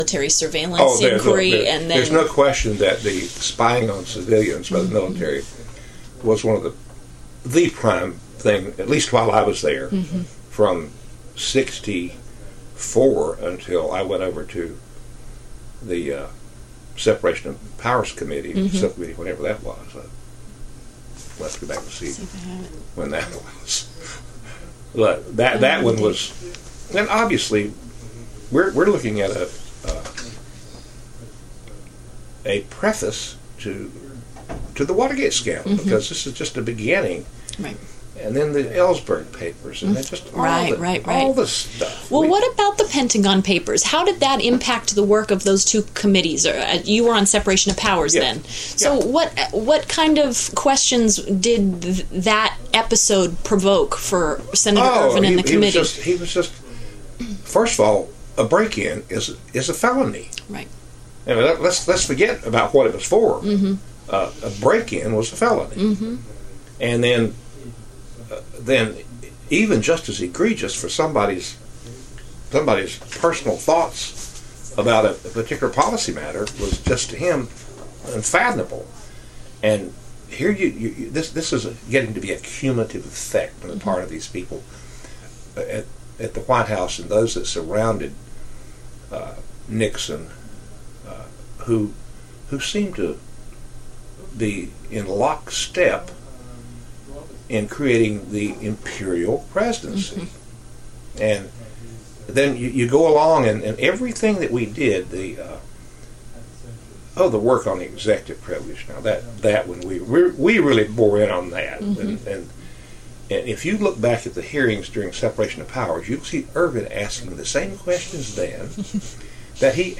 Former deputy counsel Rufus Edmisten discusses Senator Sam Ervin’s investigative and oversight work on two subcommittees in the years before the Watergate scandal unfolded.
Continue reading Edmisten’s interview